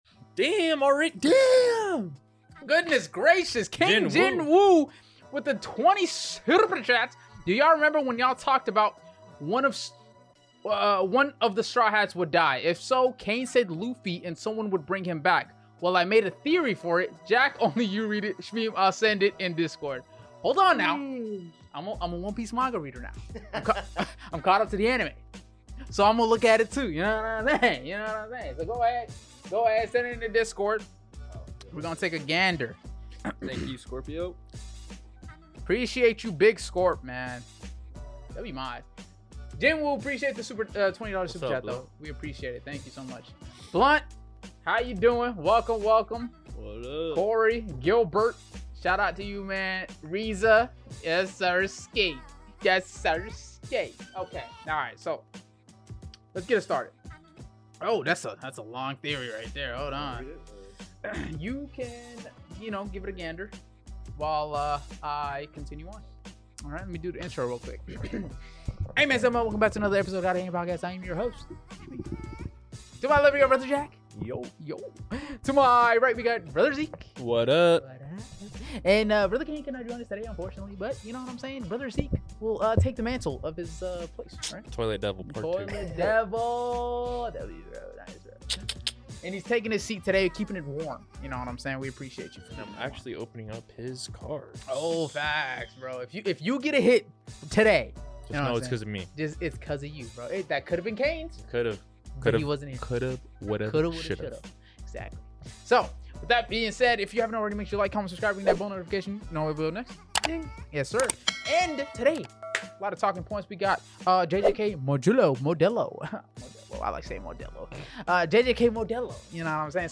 This is the podcast with a couple of young friends who just shoot the breeze talking about anything and everything, but mostly ANIME!